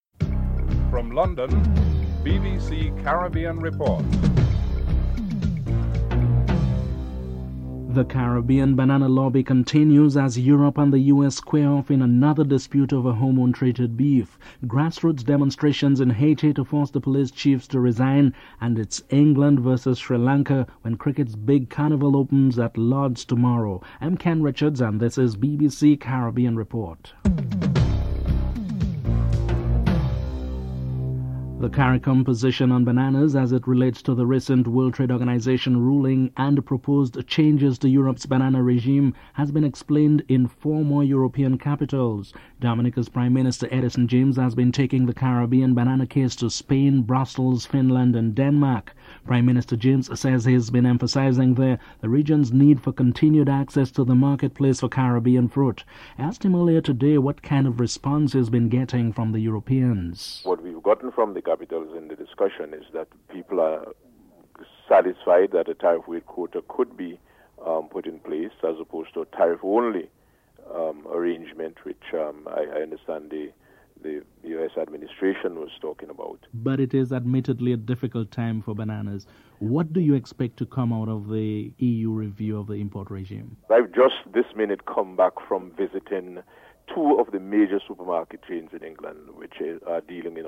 Prime Minister of St. Vincent Sir James Mitchell discusses the issue.